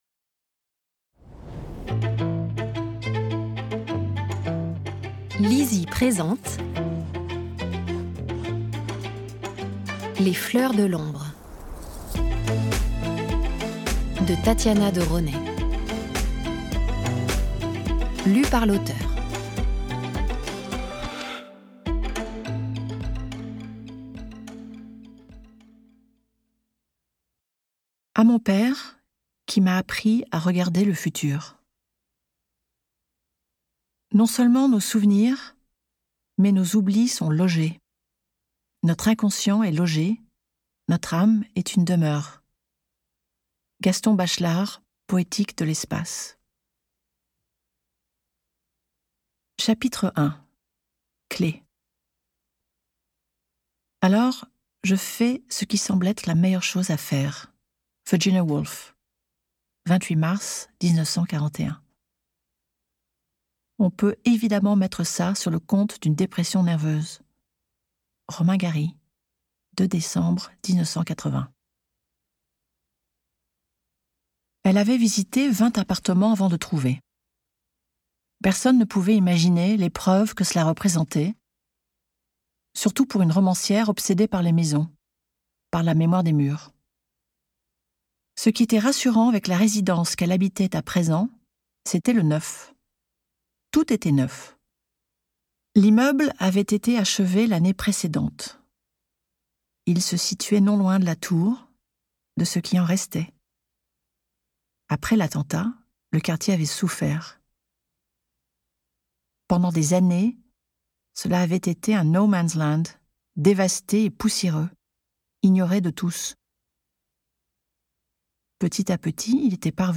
Click for an excerpt - Les Fleurs de l'ombre de Tatiana de Rosnay